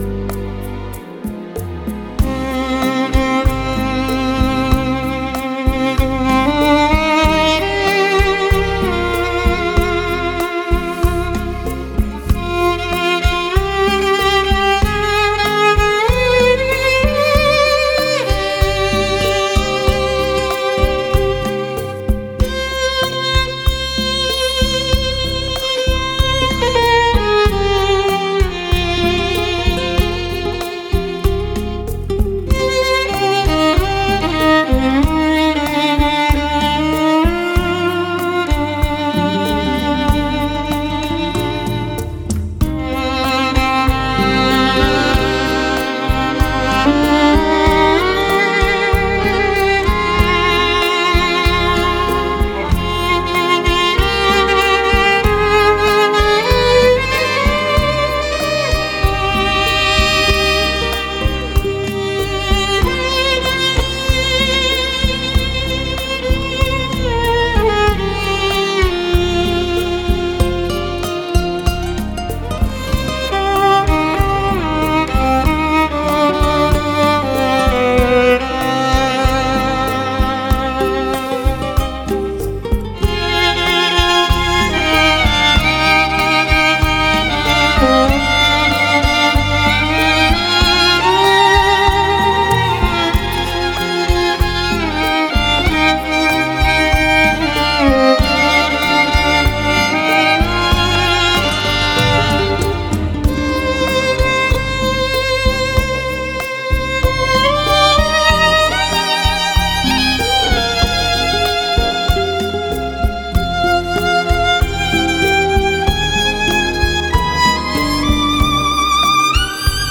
Violinist